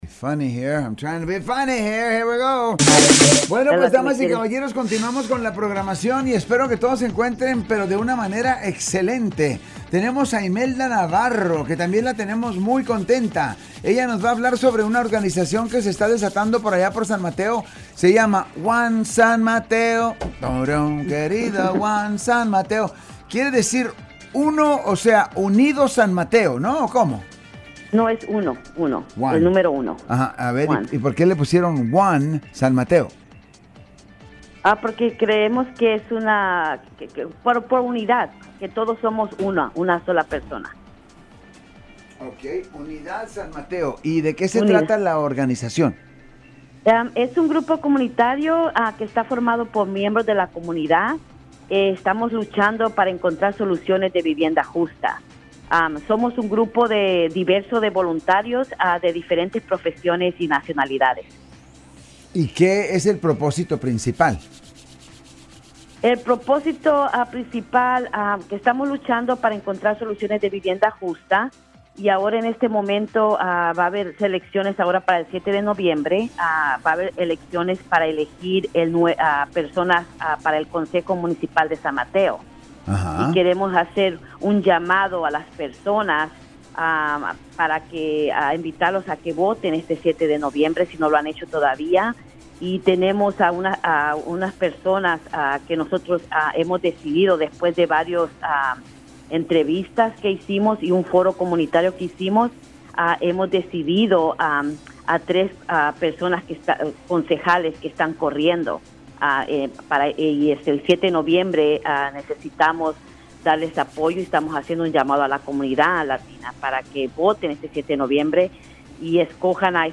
talking about housing, politics, and community engagement on San Francisco's largest Spanish-language radio station, KIQI 1010AM.